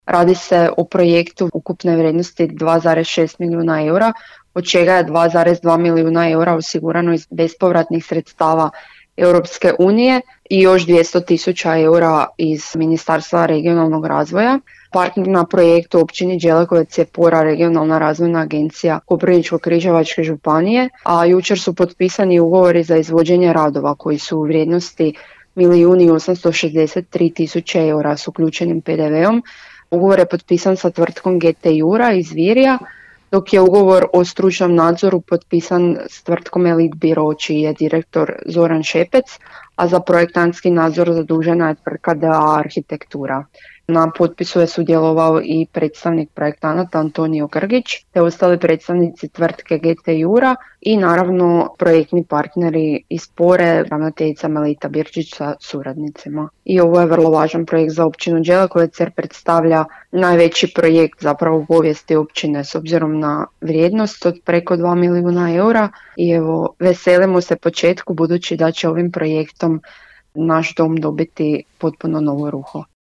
-rekla je za Podravski radio načelnica Općine Đelekovec, ujedno i voditeljica projekta Lara Samošćanec Kiš te naglasila kako je cilj da po završetku projekta Dom kulture postane središnje mjesto za održavanje kulturno-umjetničkih događaja te moderna, multifunkcionalna i energetski učinkovita zgrada u kojoj će se prezentirati kulturna baština općine, ali i cijelog područja.